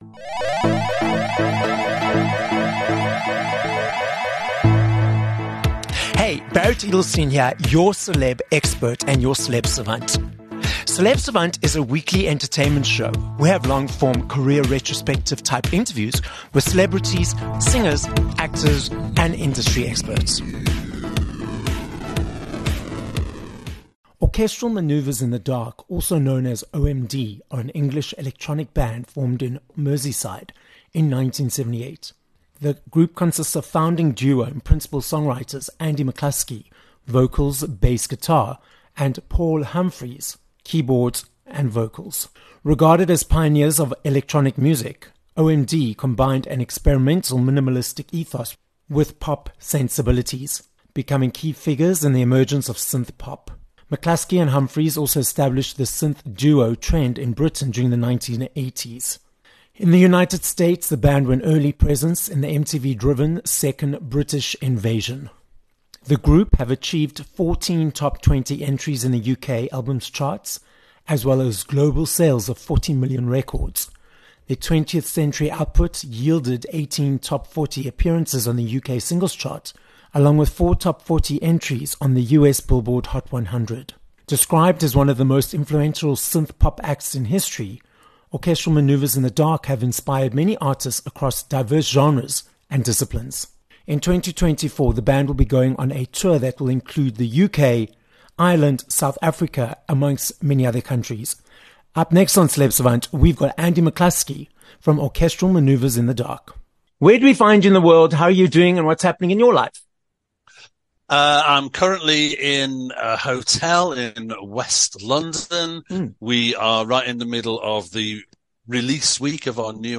26 Nov Interview with Andy McCluskey (OMD)